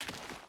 Dirt Walk 4.wav